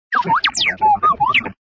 SRobotWarn.ogg